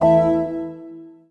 rr3-assets/files/.depot/audio/sfx/menu_sfx/rr3_ui_overheat.wav
rr3_ui_overheat.wav